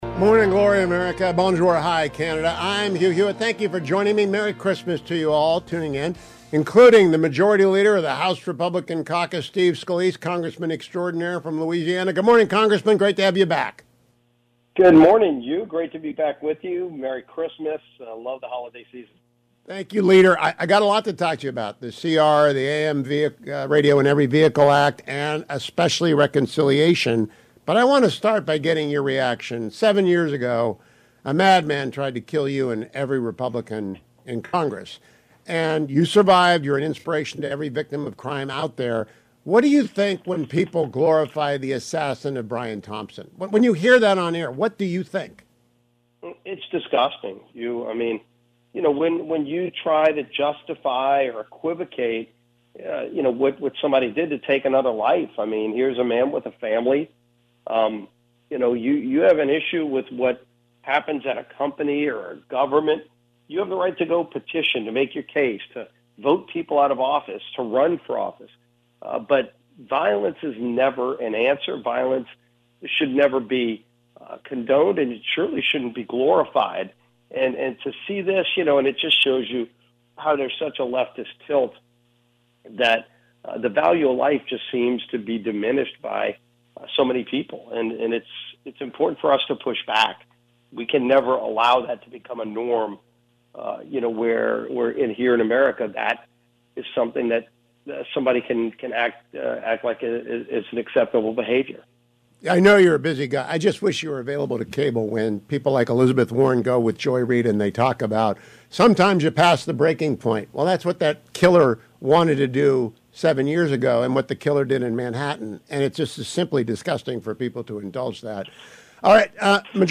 House Majority Leader Steve Scalise (R-LA) joined me this morning: